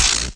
collide02.mp3